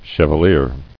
[chev·a·lier]